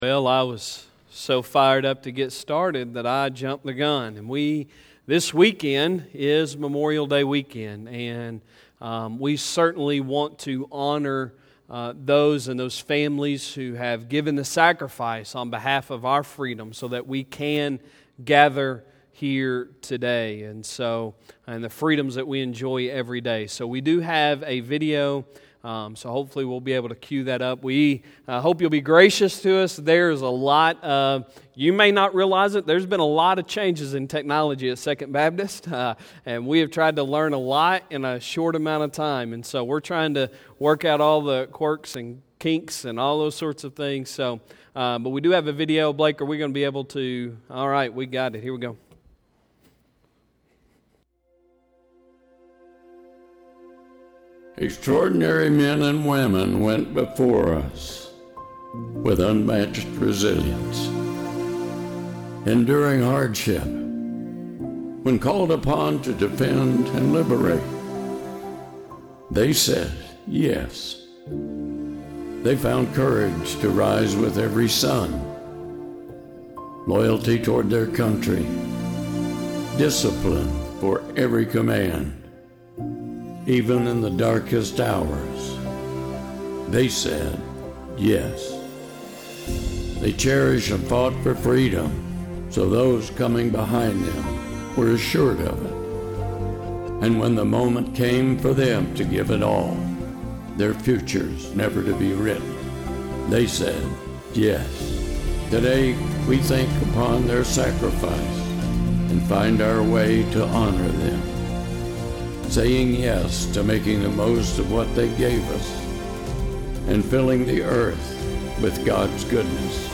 Sunday Sermon May 24, 2020